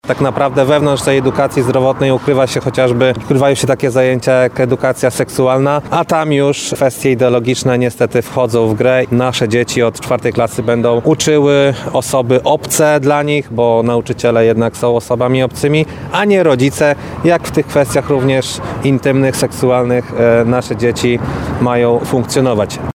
Poseł Konfederacji Bartłomiej Pejo krytykuje zmiany w szkolnictwie zapowiadane przez Ministerstwo Edukacji Narodowej.